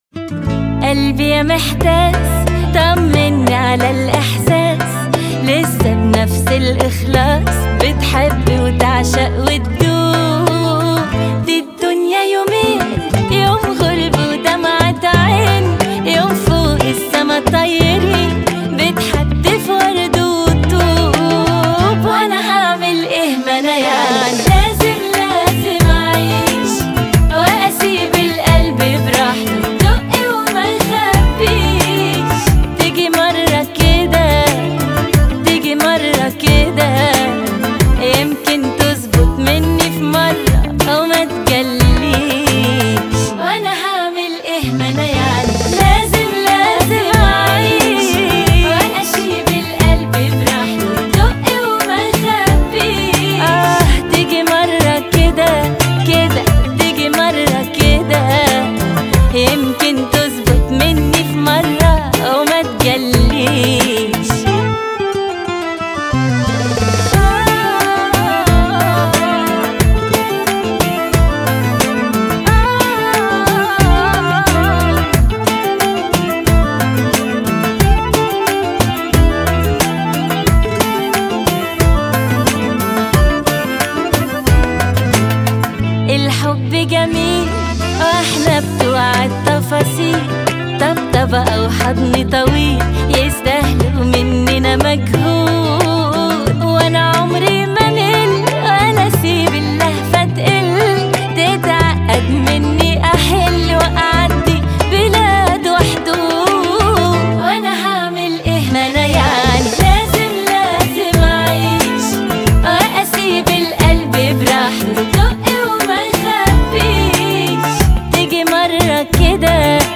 دانلود آهنگ عربی